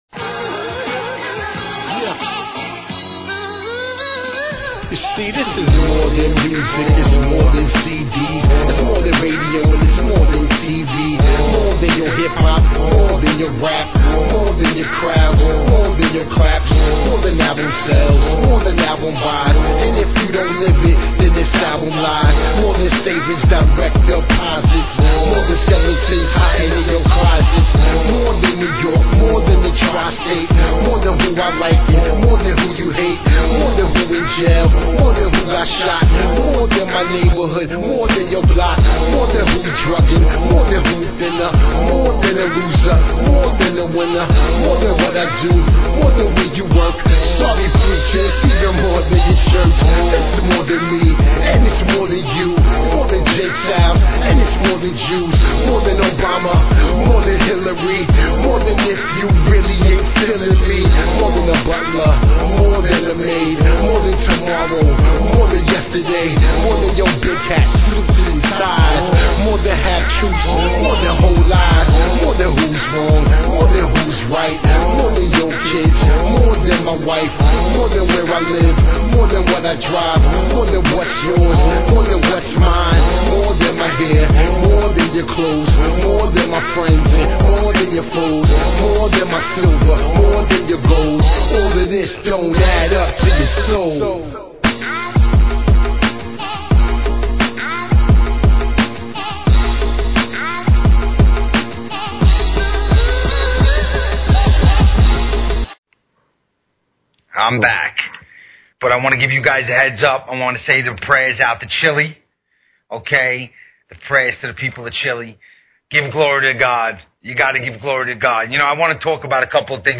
Talk Show Episode, Audio Podcast, Soldiers_of_Christ and Courtesy of BBS Radio on , show guests , about , categorized as
PREACHIN THE WORD WITH CHRISTIAN HIP HOP AND SOUL!
Spreading the good word; plus hip hop and soul inspired by the Gospel!